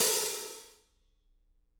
R_B Hi-Hat 07 - Close.wav